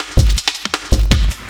LOOP06SD07-R.wav